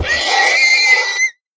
horse_death.ogg